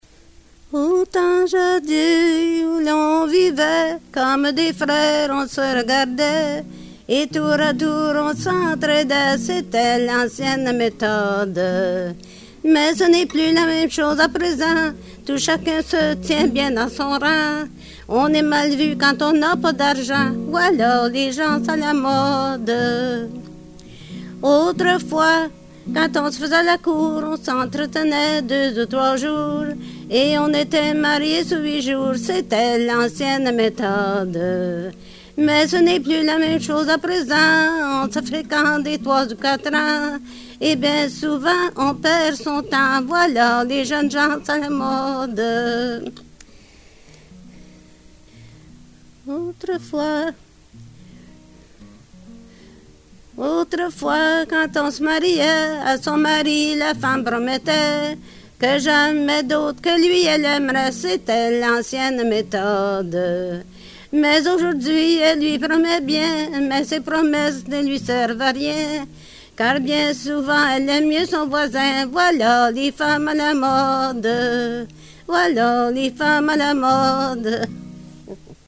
Folk Songs, French--New England
Excerpt from interview